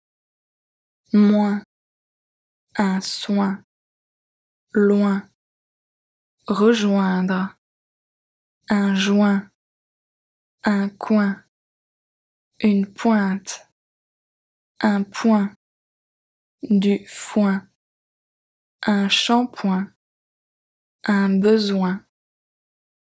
Lesson 17 - Listening practice - 35